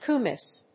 音節ku・miss 発音記号・読み方
/kuːmís(米国英語)/